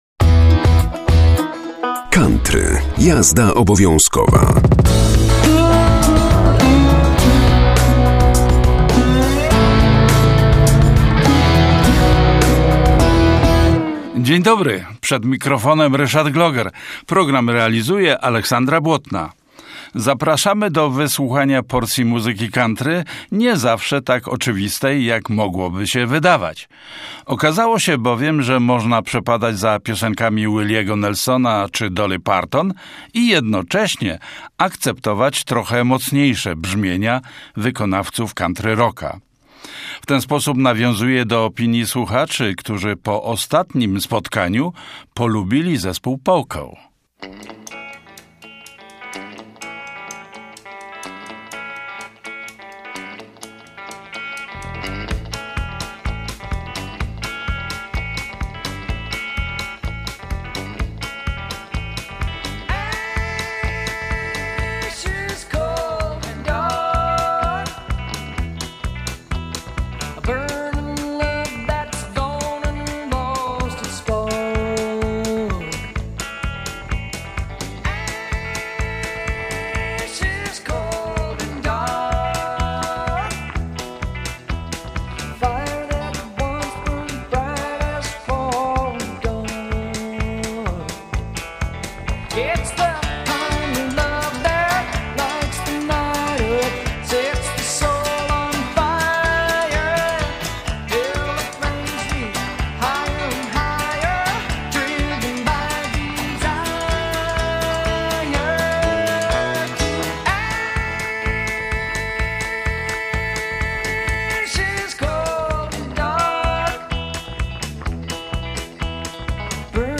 COUNTRY - JAZDA OBOWIĄZKOWA 30.11.2025